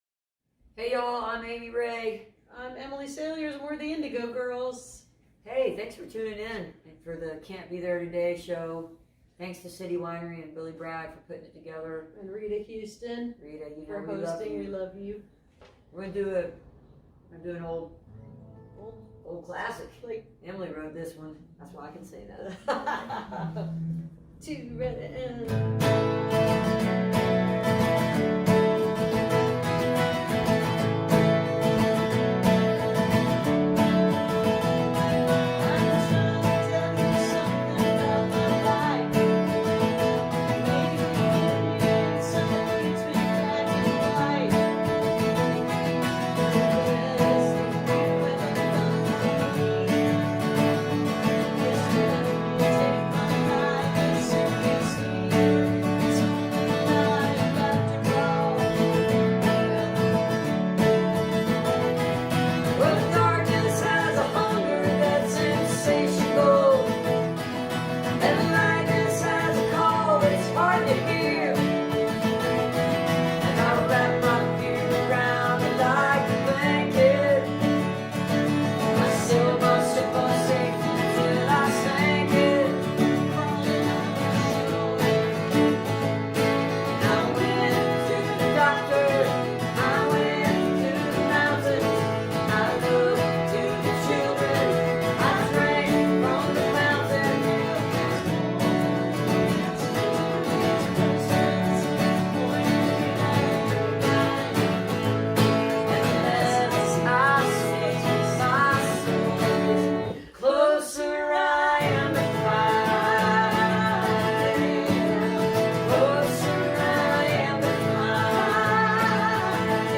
(captured from the youtube broadcast)